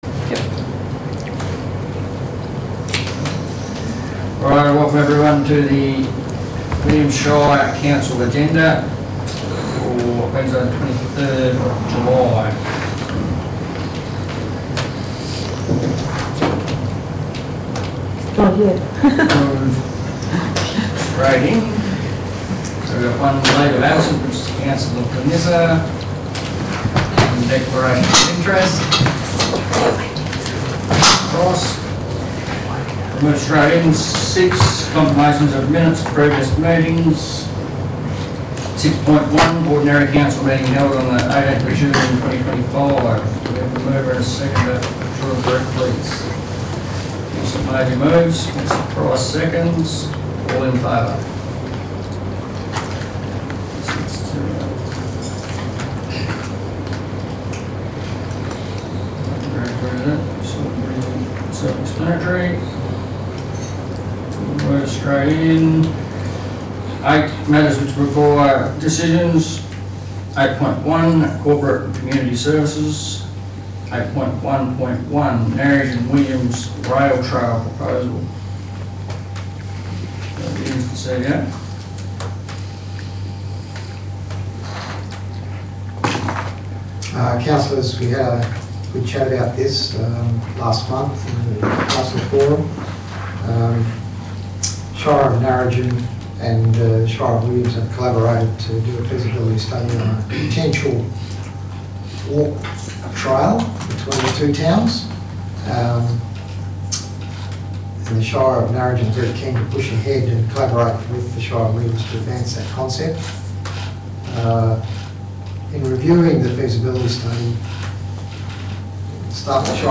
Ordinary Meeting of Council - Wednesday 23rd July 2025 » Shire of Williams